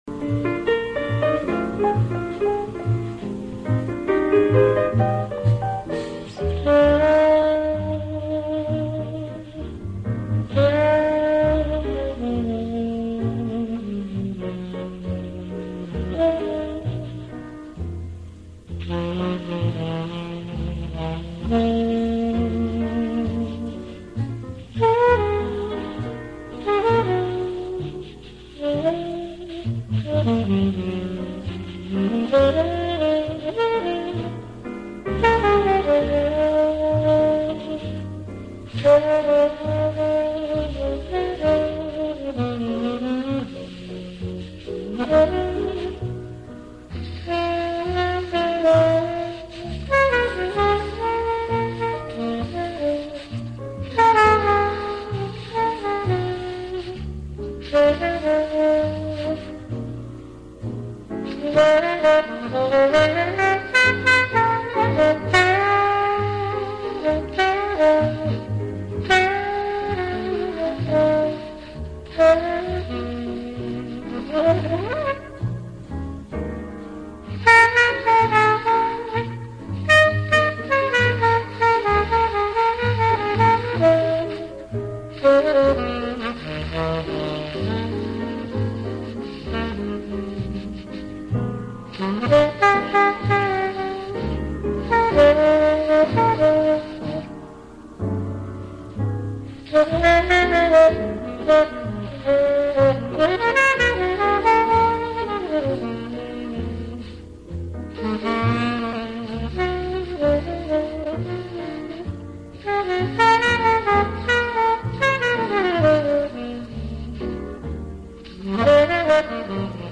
昏暗灯光中，音乐的色彩依然鲜明，曲风摇摆生姿，游走於真实与虚幻